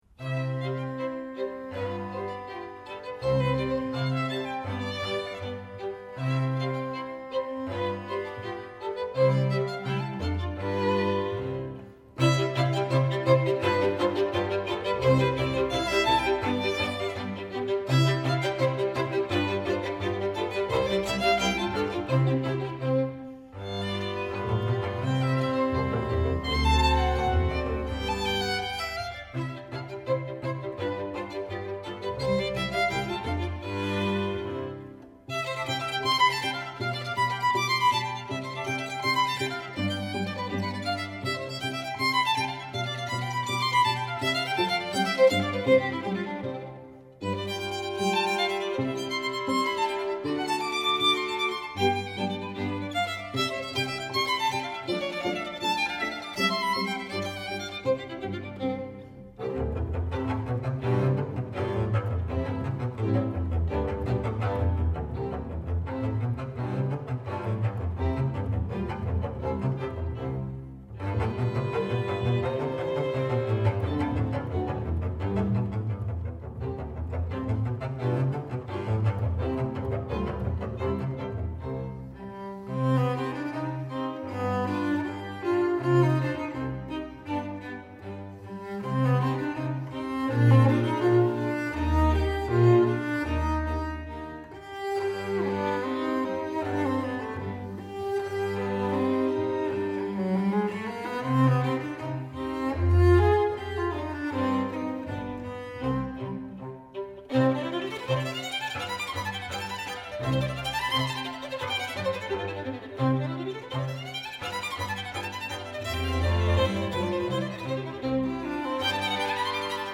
violin
cello
double bass